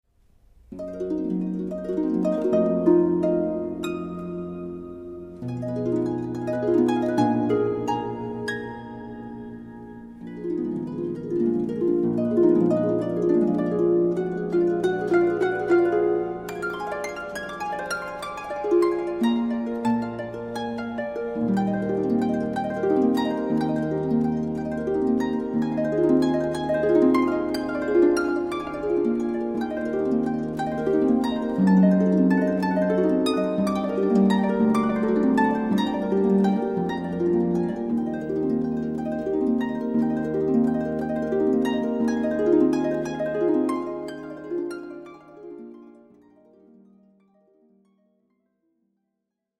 A beautiful cd with 77 minutes harp solo music.